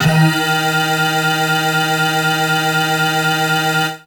55bg-syn12-d#3.wav